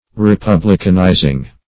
Republicanizing synonyms, pronunciation, spelling and more from Free Dictionary.
republicanizing.mp3